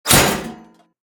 metal3.ogg